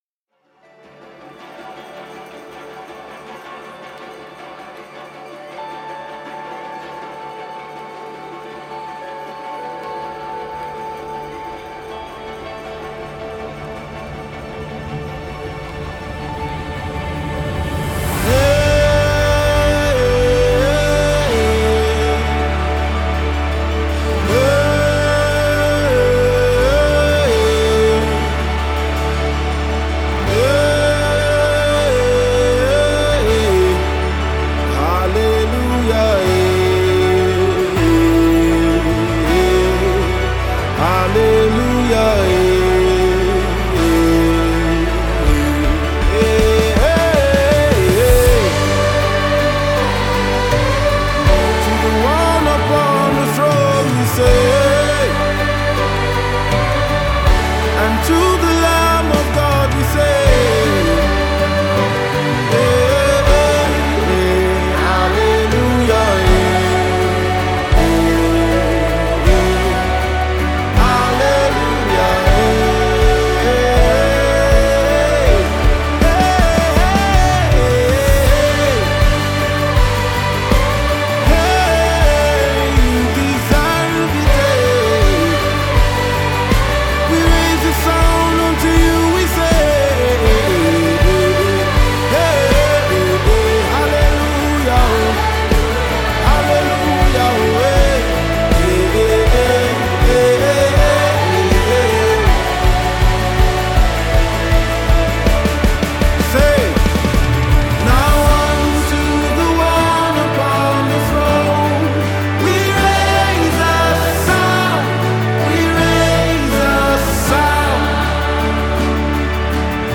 Gospel
uplifting anthem of praise and worship
an amazing vocal group based in Lagos, Nigeria.